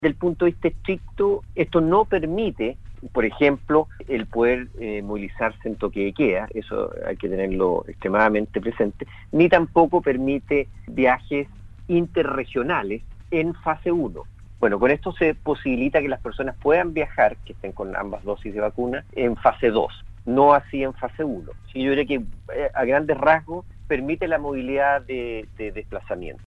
En conversación con Radio Sago, el Seremi de Salud de la Región de Los Lagos, Alejandro Caroca, se refirió al sentido de esta nueva medida implementada por el Gobierno, que desde el miércoles está a disposición de quienes cumplan los requisitos de tener las dos dosis de la vacuna contra el Covid-19. La autoridad regional explicó que este pase de movilidad no significa una liberalización de todas las restricciones ahora, detallando los alcances de la medida.